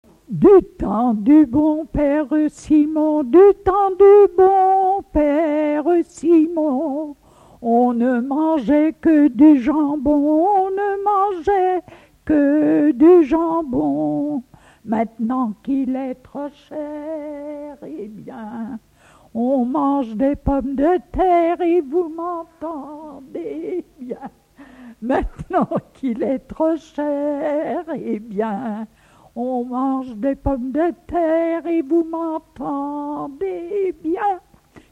ronde d'école
Pièce musicale inédite